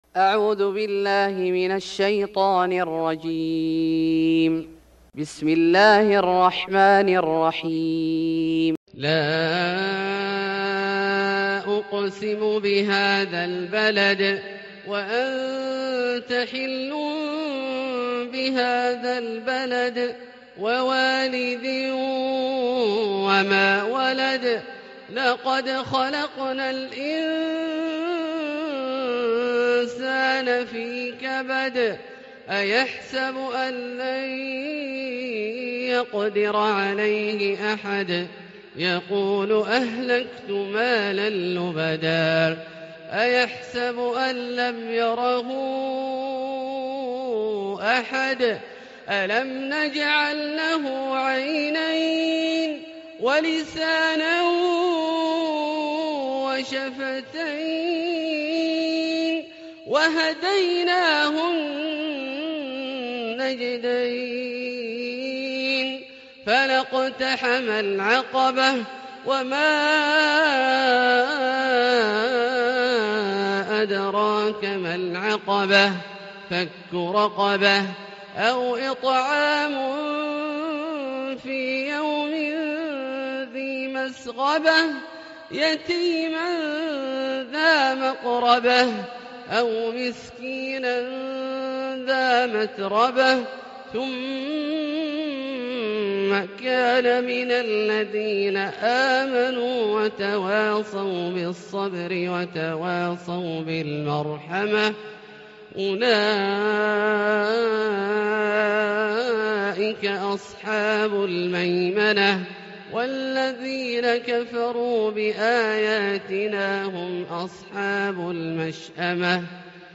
سورة البلد Surat Al-Balad > مصحف الشيخ عبدالله الجهني من الحرم المكي > المصحف - تلاوات الحرمين